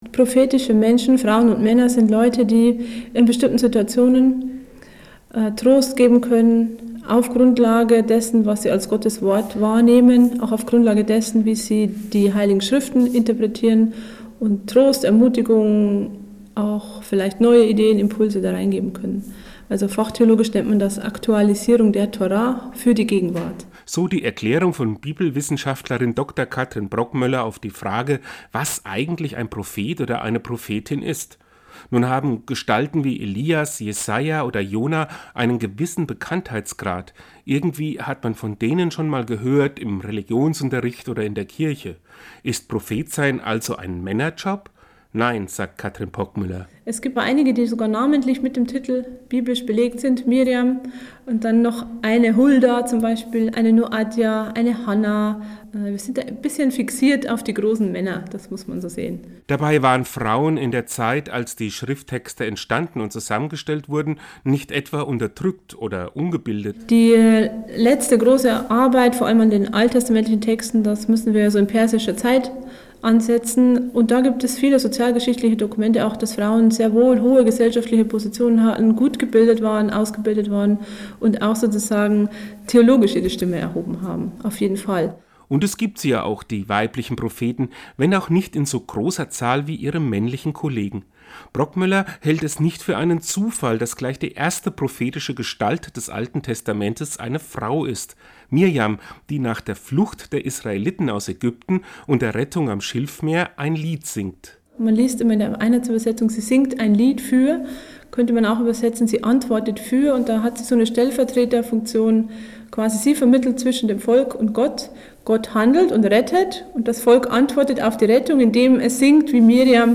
Seinen Radiobeitrag finden Sie unten zum Download!